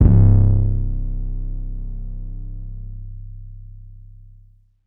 Kitchen808_YC.wav